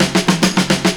FILL 3    -R.wav